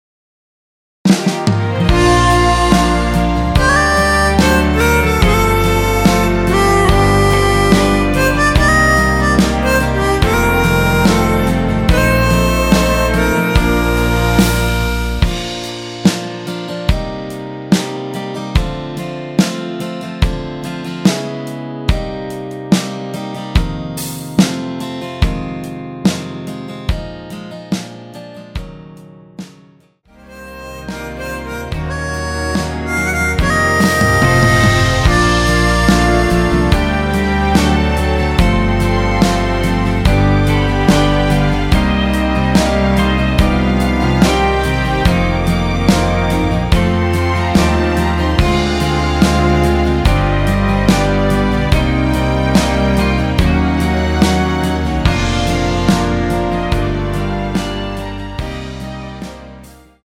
원키에서 (-2)내린 MR 입니다.
Bb
앞부분30초, 뒷부분30초씩 편집해서 올려 드리고 있습니다.
중간에 음이 끈어지고 다시 나오는 이유는